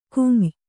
♪ kuy`